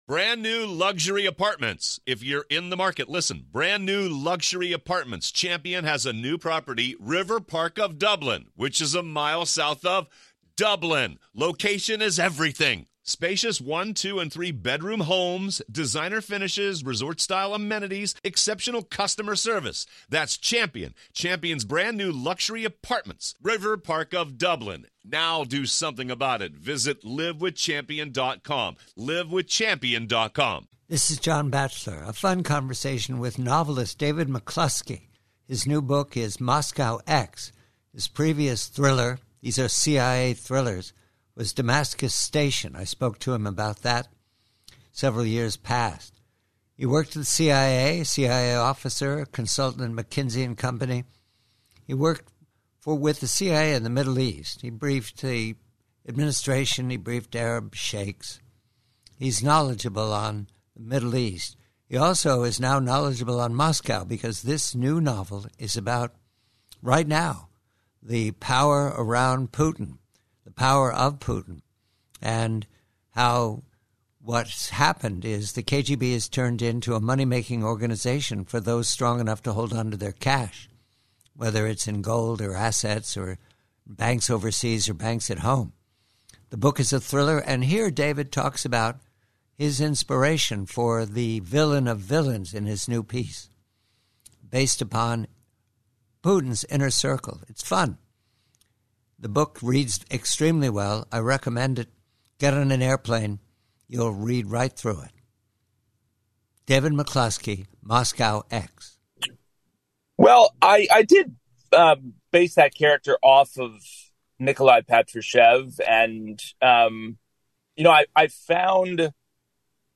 PREVIEW: From a longer conversation